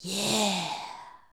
YEAH 1.wav